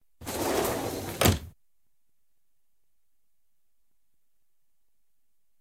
doorsclosed.ogg